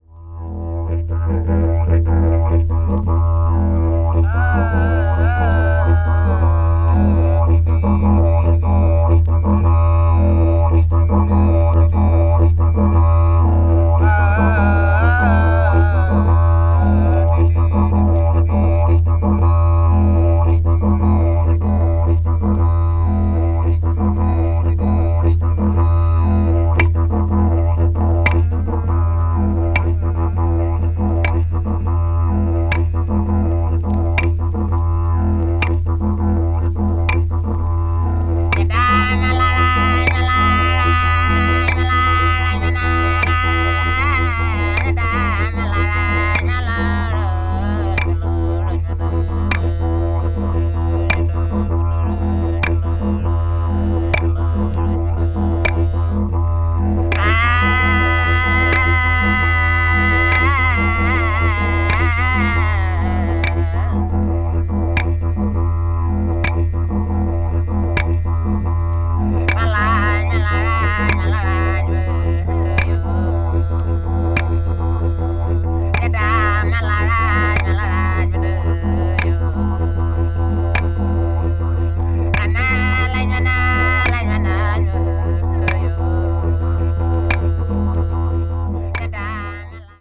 Arnhem Land Popular Classics : Aboriginal Dance Songs with Didjeridu Accompaniment
media : EX-/EX-(薄いスリキズによるわずかなチリノイズが入る箇所あり)
aborigini   australia   didjeridu   ethnic music   folk   traditional   world music